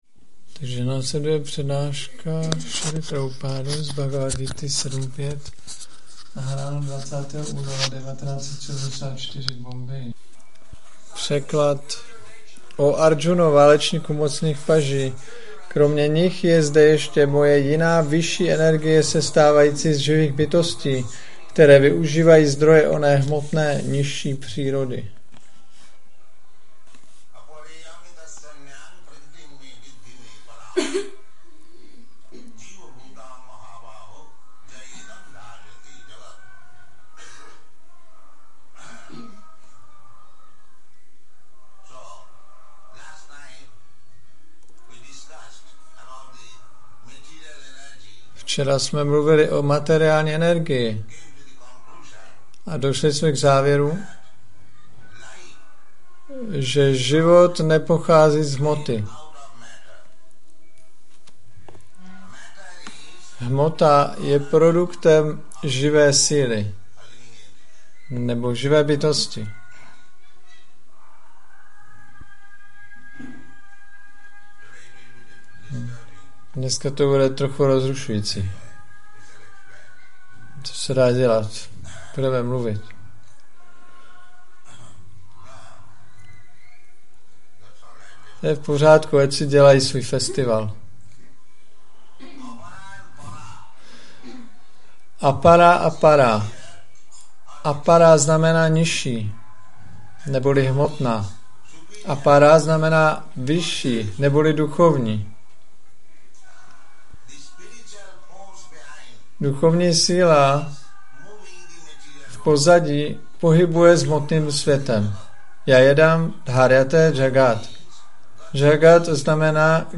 1974-02-20-ACPP Šríla Prabhupáda – Přednáška BG-7.5 Bombay